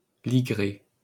Ligré (French pronunciation: [liɡʁe]